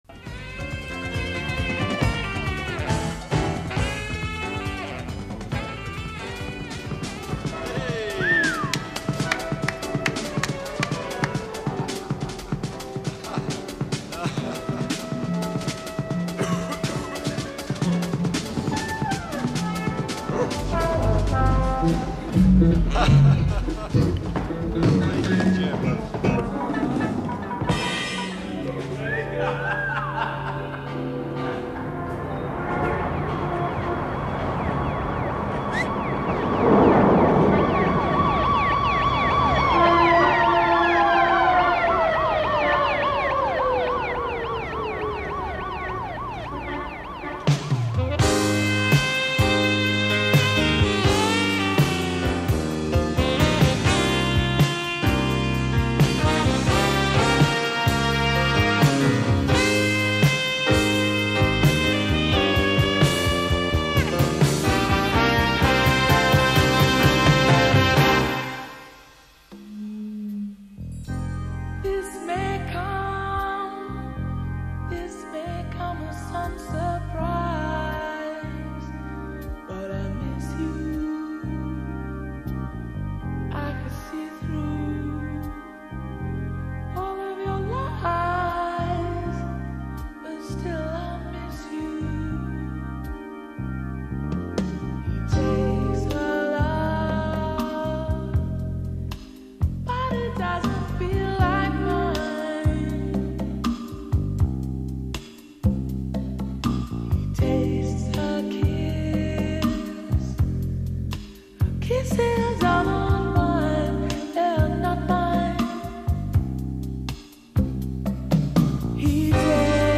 Soul / SmoothJazz / R&B